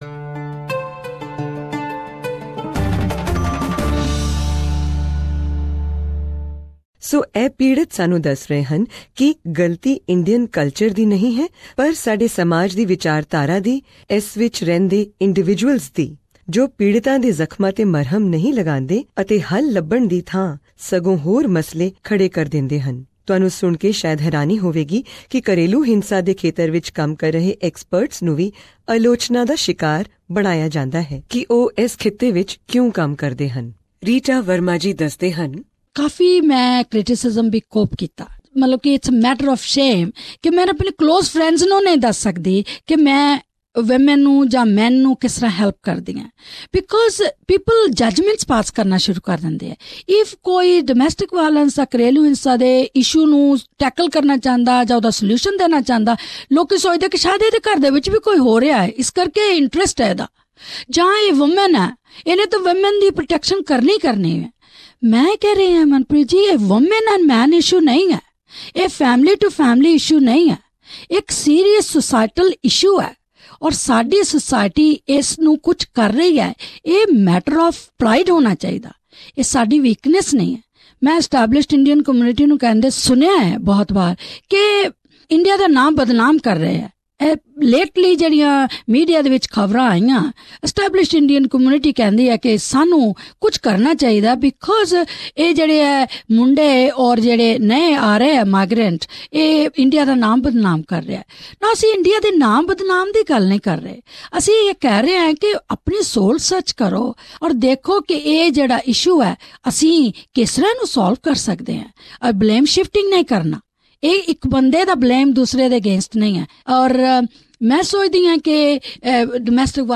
This is the sixth and final episode of our multi-award winning documentary on family violence in the Indian community of Australia, the Enemy Within.
In this last episode of the Enemy Within, police, victims and experts talk of things that we should all be mindful of and about potential solutions.